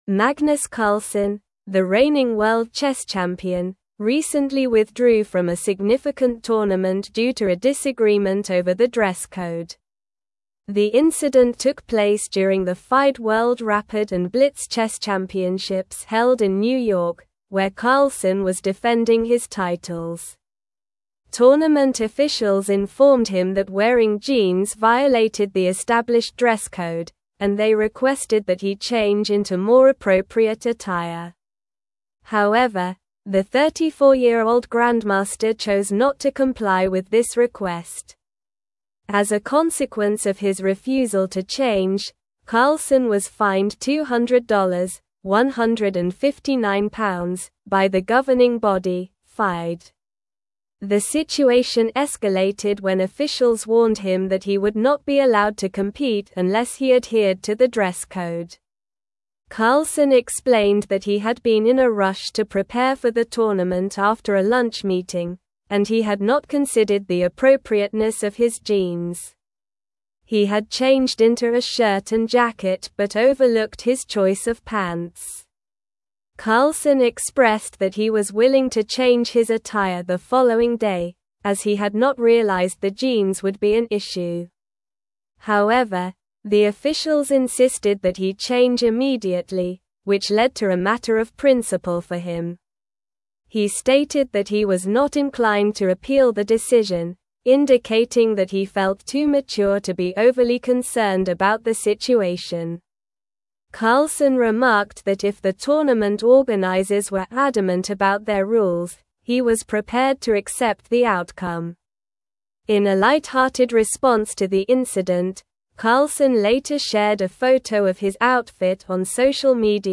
Slow
English-Newsroom-Advanced-SLOW-Reading-Carlsen-Withdraws-from-Tournament-Over-Dress-Code-Dispute.mp3